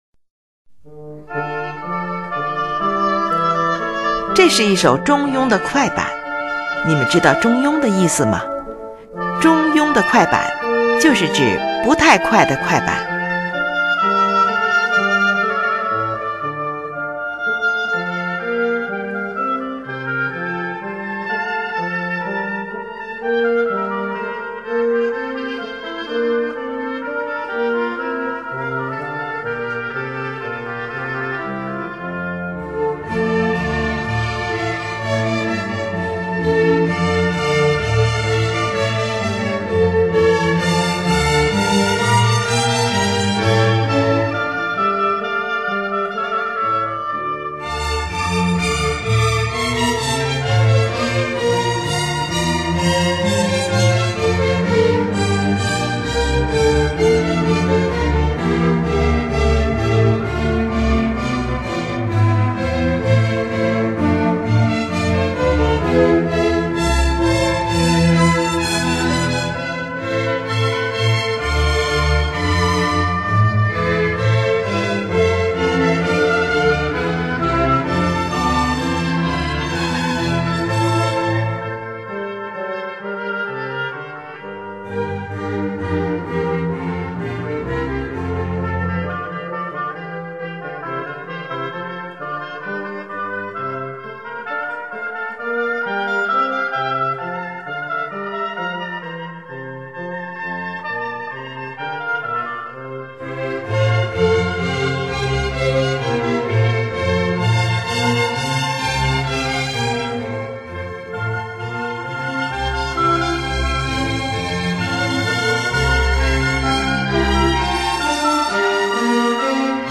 乐器使用了小提琴、低音提琴、日耳曼横笛、法兰西横笛、双簧管、圆号、小号等